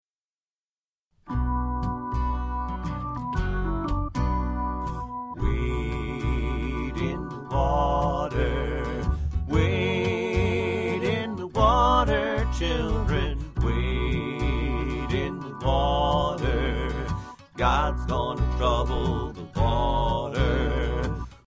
Folk Song Lyrics and Sound Clip